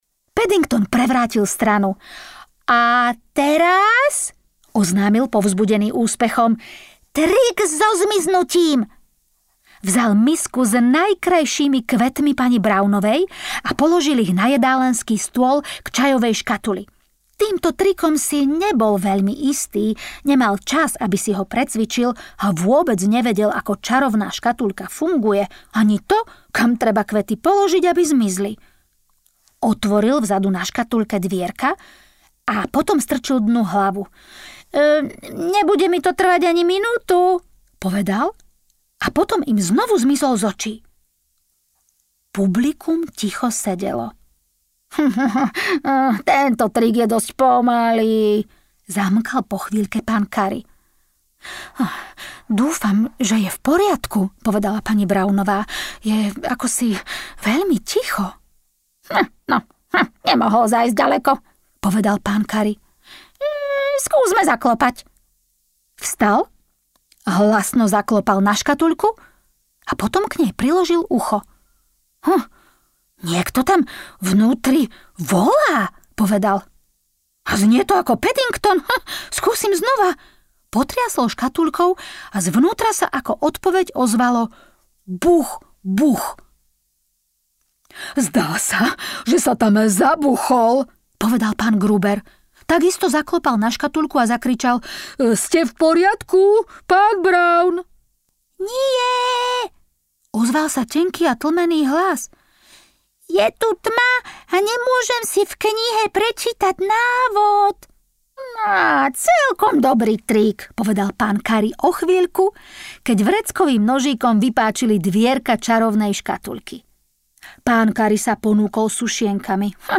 Medvedík Paddington audiokniha
Ukázka z knihy
medvedik-paddington-audiokniha